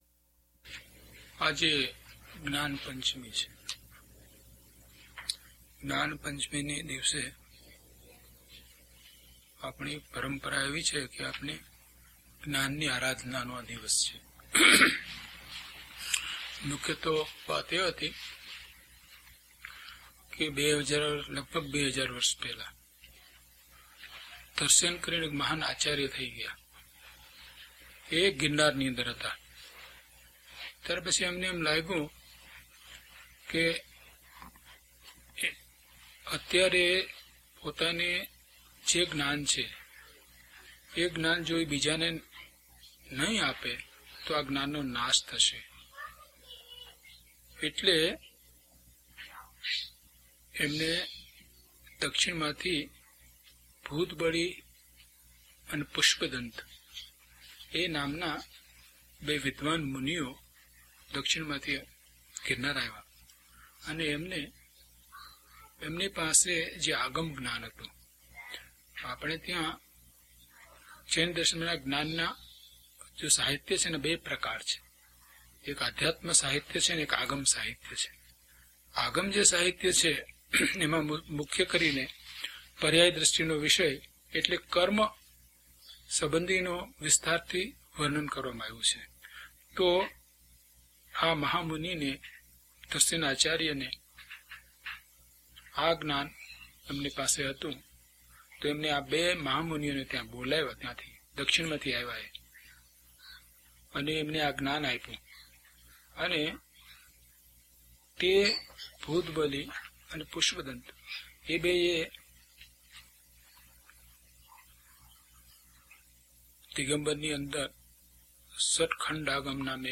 DHP035 Jad Bhave Jad Pariname Part 2 - Pravachan.mp3